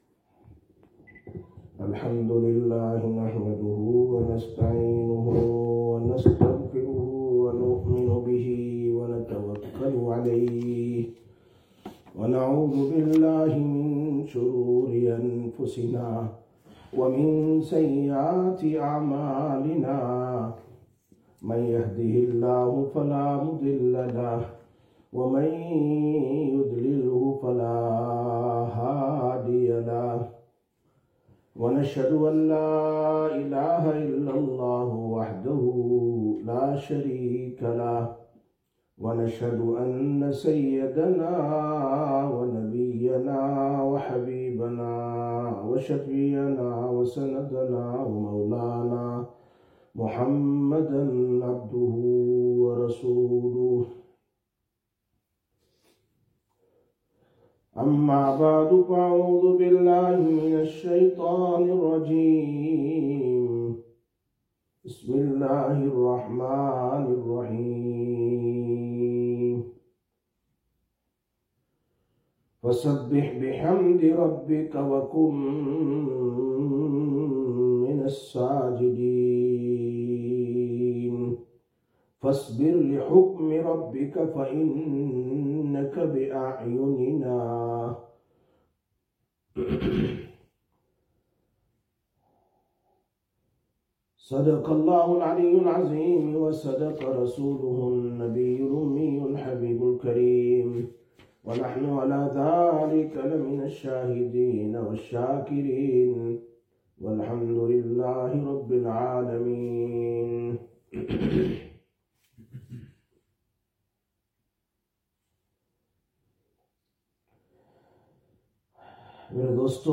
22/01/2025 Sisters Bayan, Masjid Quba